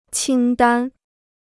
清单 (qīng dān): liste; énumération.